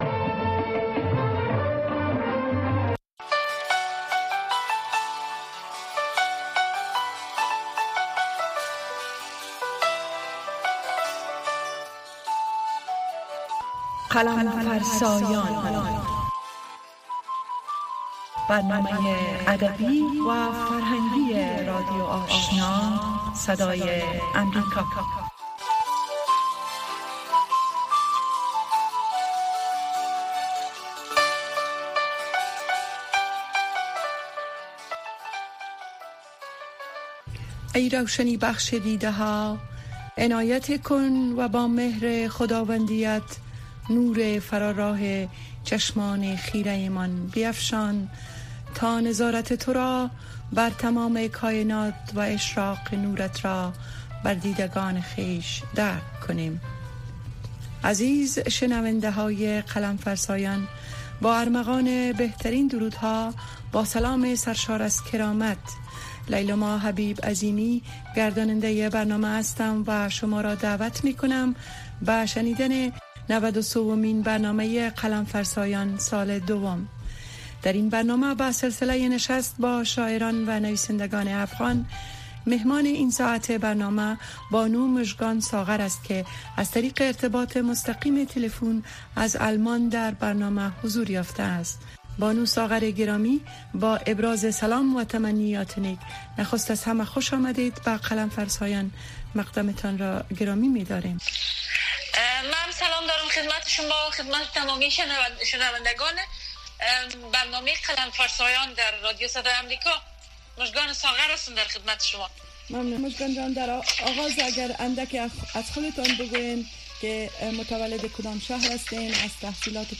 گفت و شنود بحث رادیویی است که در آن موضوعات مهم خبری با حضور تحلیلگران و مقام های حکومت افغانستان به بحث گرفته می شود. گفت و شنود به روزهای سه شنبه و جمعه به ترتیب به مسایل زنان و صحت اختصاص یافته است.